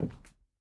added stepping sounds
Parquet_Floor_Mono_03.wav